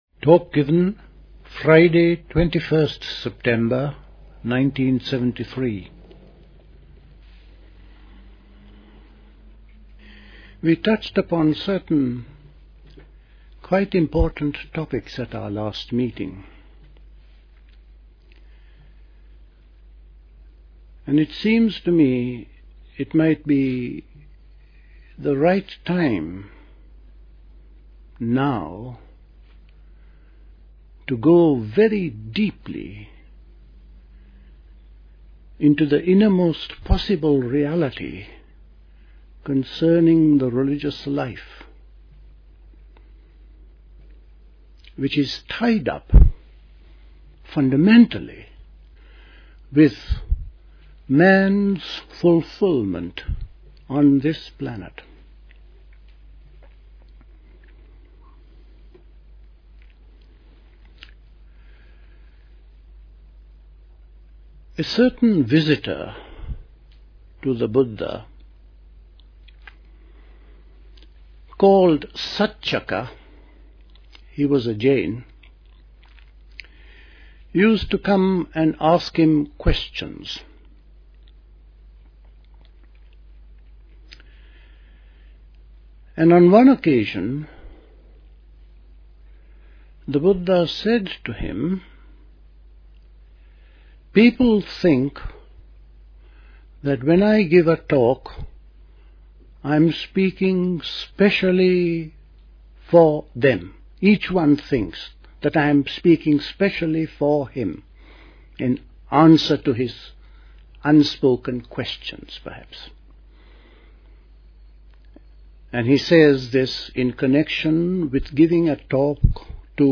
A talk
at Dilkusha, Forest Hill, London